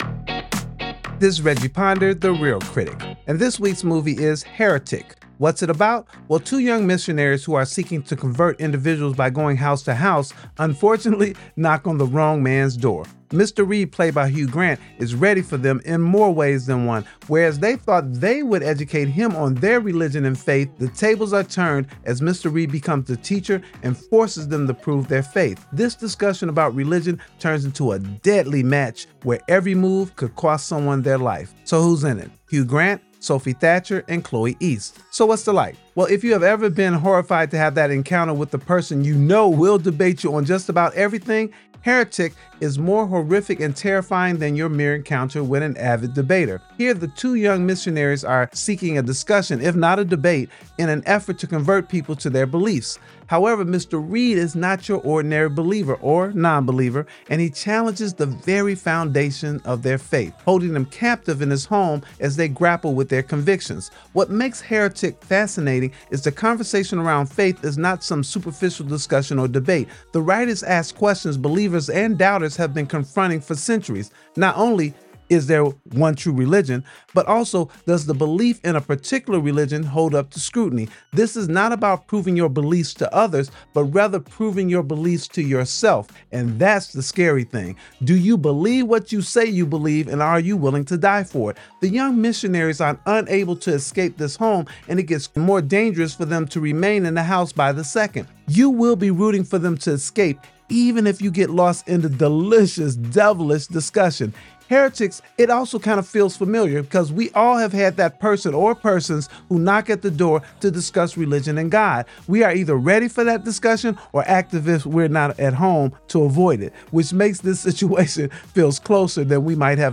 review